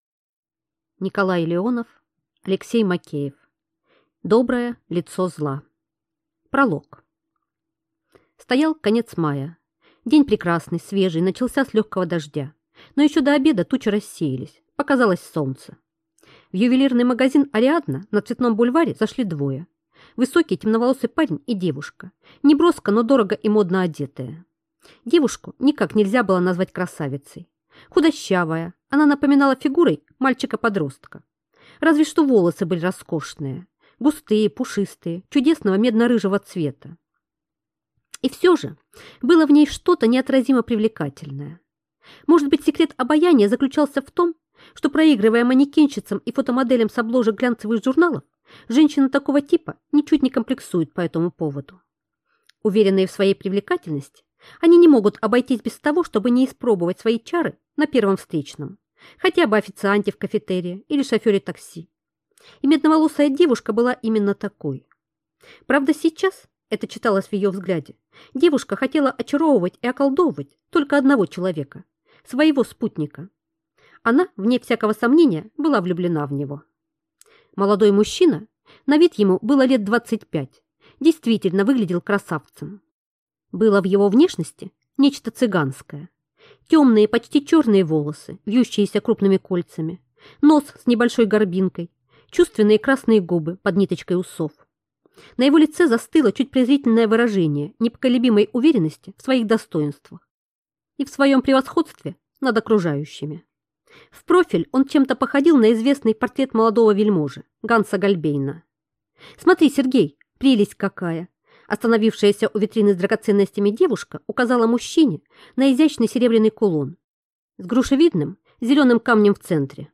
Аудиокнига Доброе лицо зла | Библиотека аудиокниг